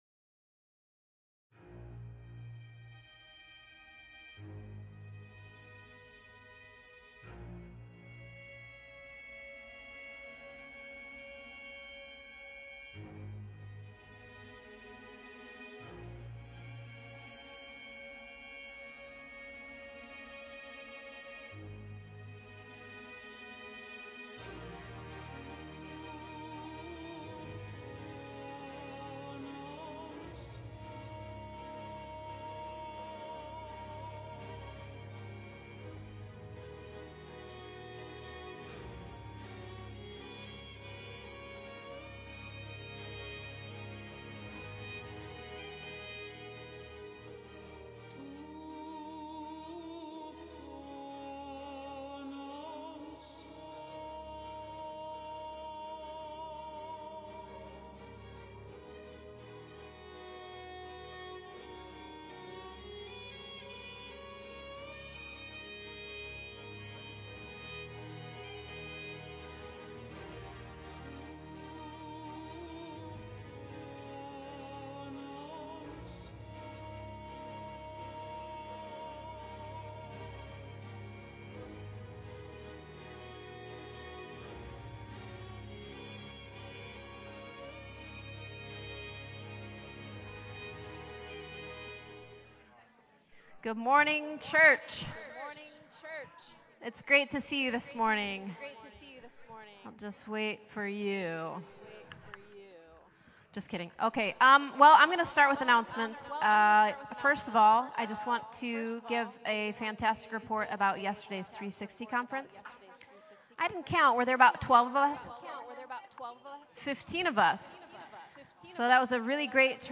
Our fantastic worship team leads us as we praise God on this beautiful Sunday morning.